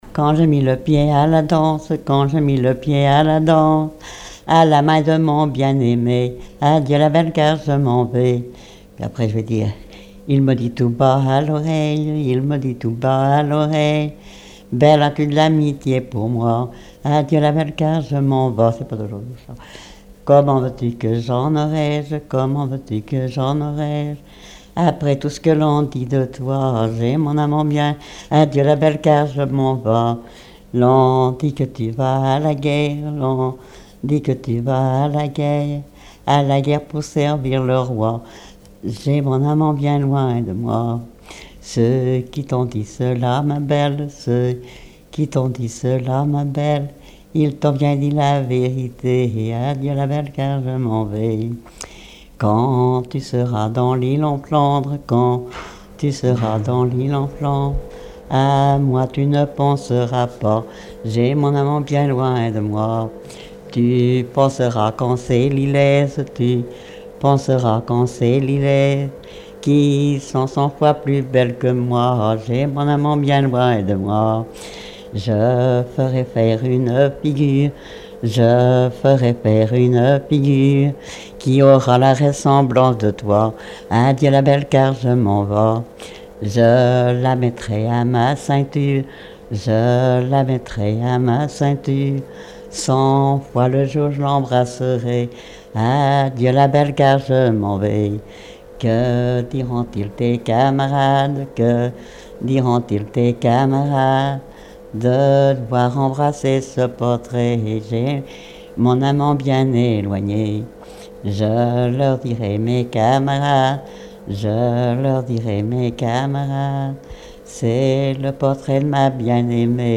danse : ronde à la mode de l'Epine
Genre laisse
Pièce musicale inédite